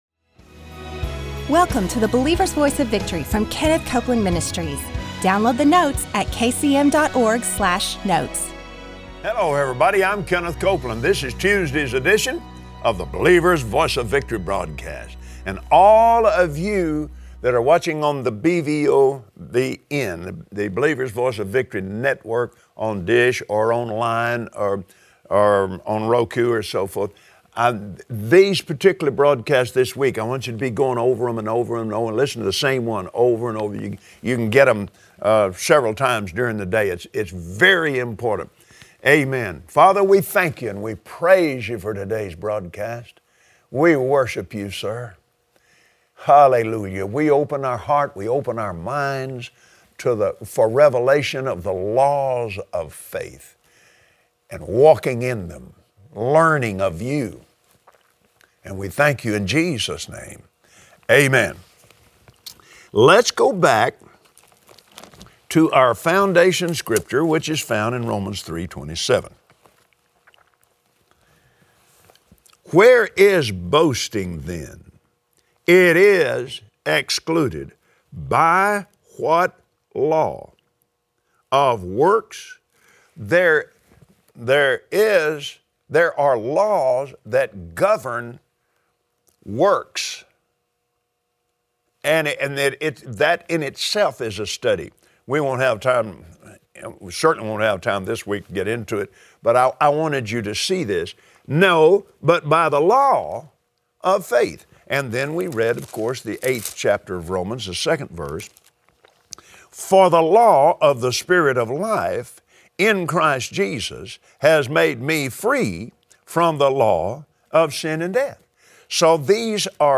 Believers Voice of Victory Audio Broadcast for Tuesday 03/22/2016Today, on the Believer’s Voice of Victory, Kenneth Copeland gives a practical lesson on activating faith in the name of Jesus and using principals that govern faith.